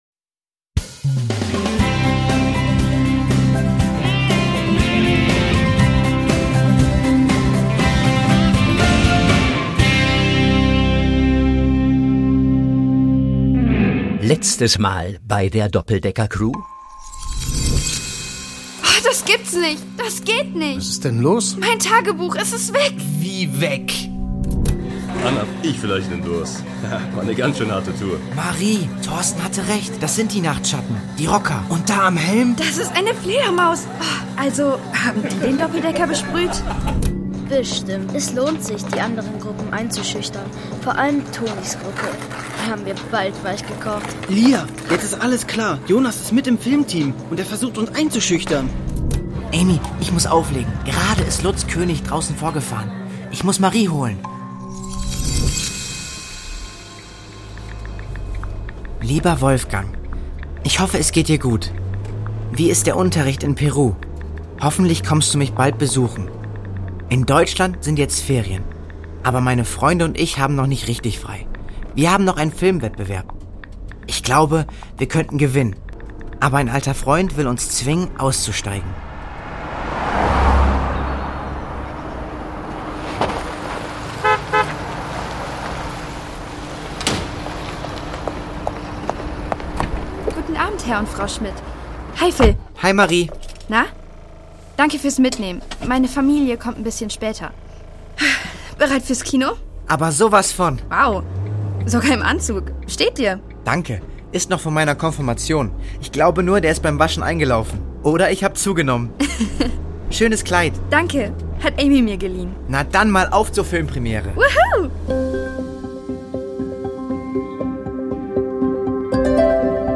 Download - Island 6: Vom Pferd erzählt … (2/3) | Die Doppeldecker Crew | Hörspiel für Kinder (Hörbuch) | Podbean